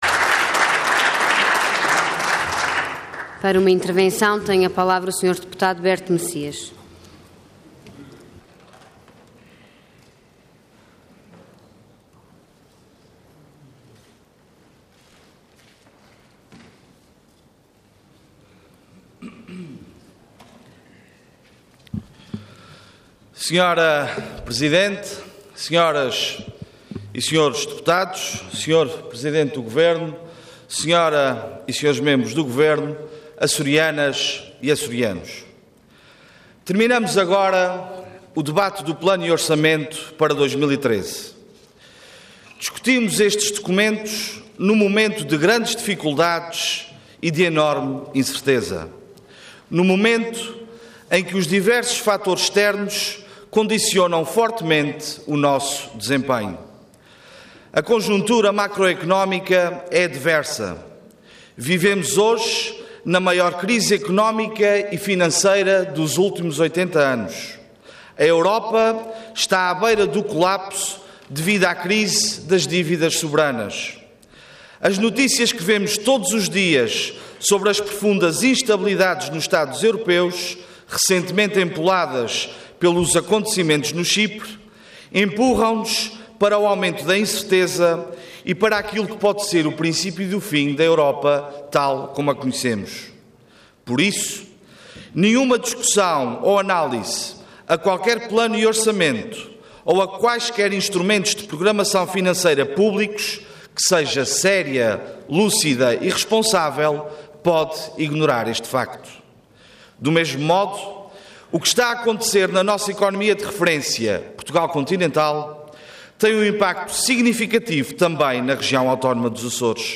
Intervenção Intervenção de Tribuna Orador Berto Messias Cargo Deputado Entidade PS